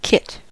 kit: Wikimedia Commons US English Pronunciations
En-us-kit.WAV